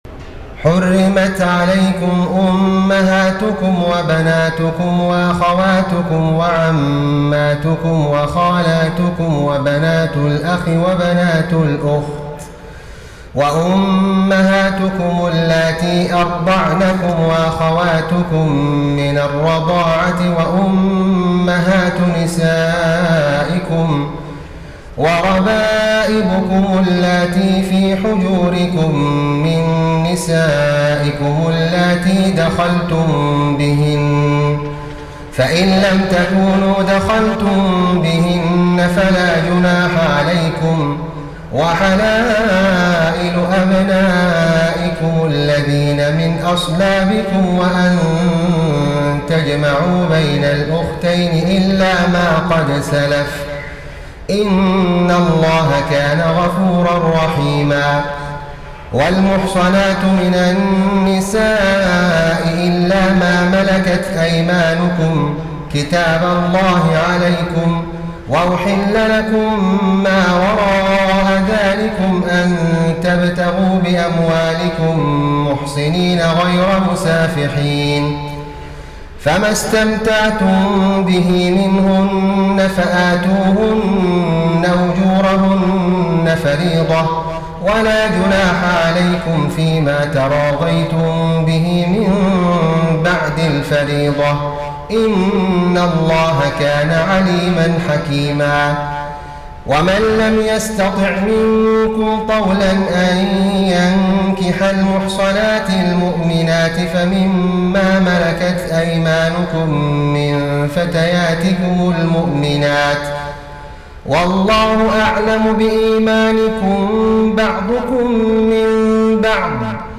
تهجد ليلة 25 رمضان 1433هـ من سورة النساء (23-99) Tahajjud 25 st night Ramadan 1433H from Surah An-Nisaa > تراويح الحرم النبوي عام 1433 🕌 > التراويح - تلاوات الحرمين